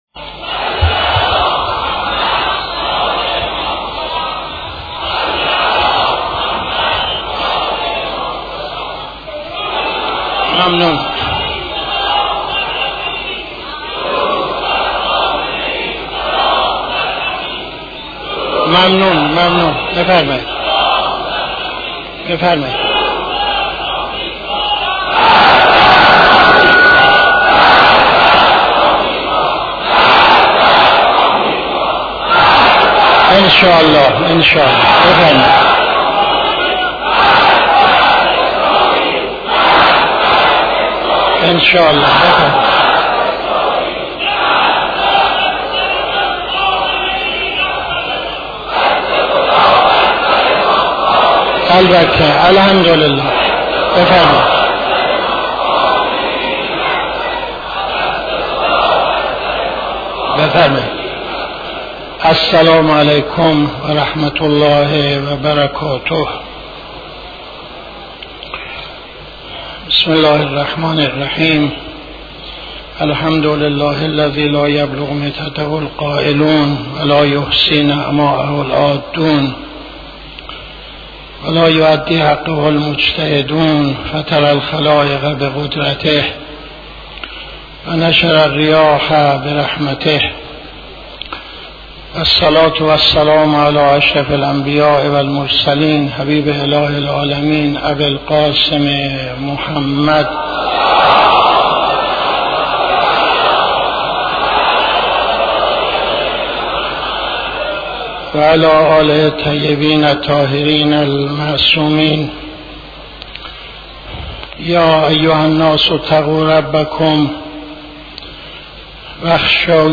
خطبه اول نماز جمعه 08-08-77